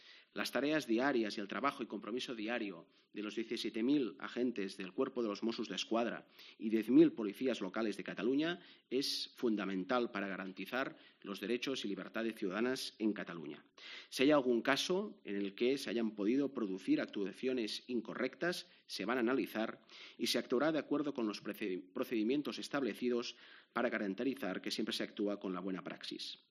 En una comparecencia y rueda de prensa realizada esta tarde, el vicepresidente se ha referido a los disturbios y demás incidentes ocurridos durante las protestas que han tenido lugar en los últimos días en Cataluña a fin de expresar el rechazo al encarcelamiento del rapero Pablo Hasel.